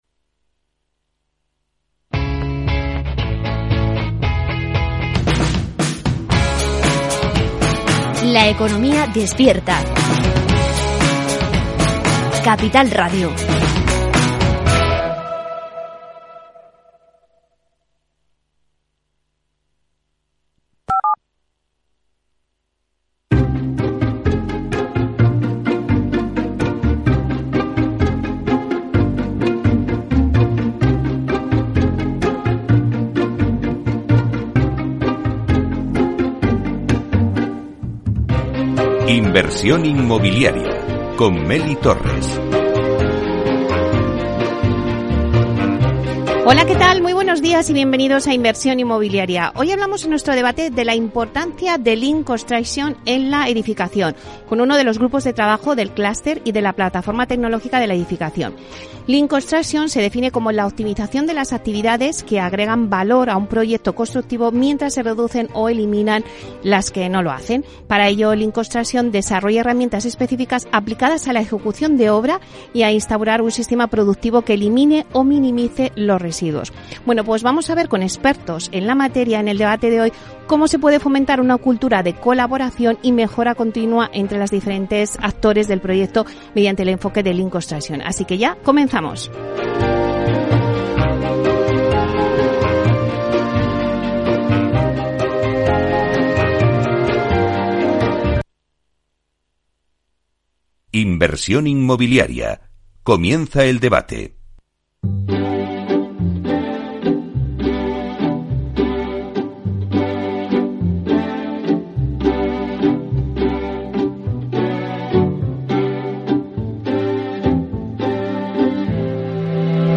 Inversión Inmobiliaria ofrece las claves para que el inversor realice la mejor operación. Le tomamos el pulso al sector con la noticia inmobiliaria de la semana, análisis de mercado y un debate con la actualidad del sector para buscar oportunidades de negocio con nuestros mejores expertos inmobiliarios.